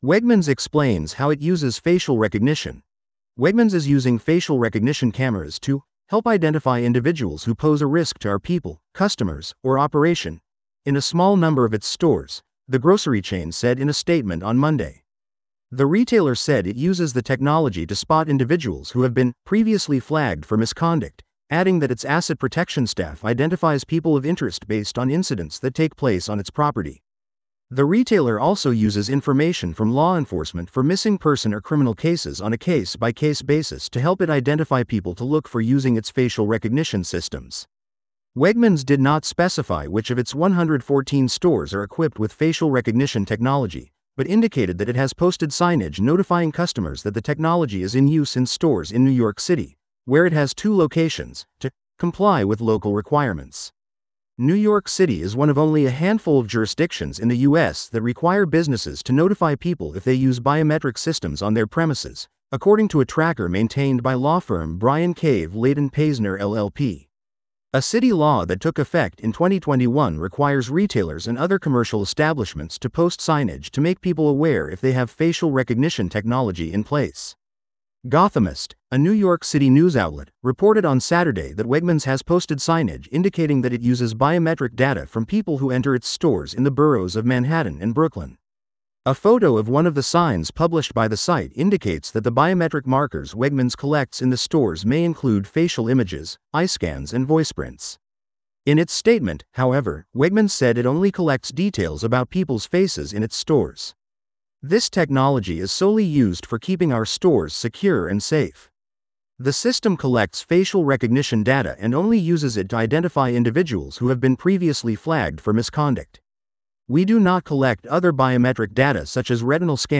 This audio is auto-generated.